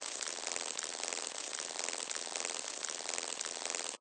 beetle_wings_long.ogg